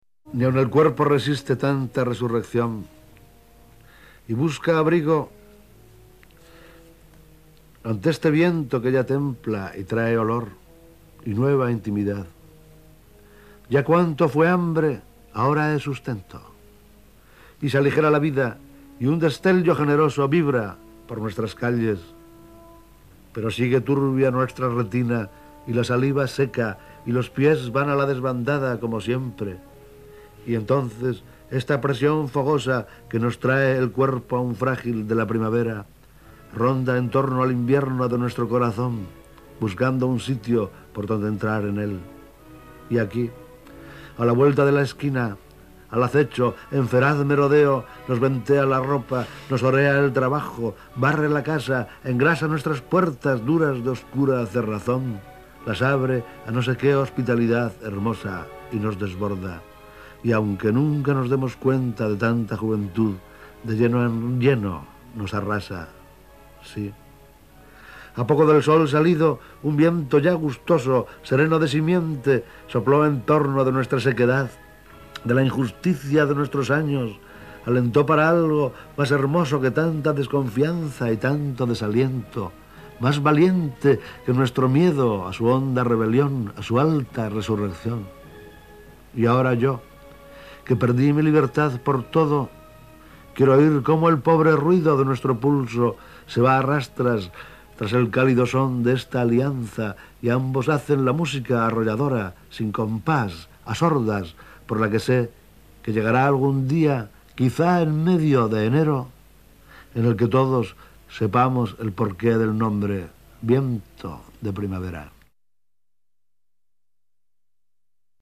Autor del audio: el propio autor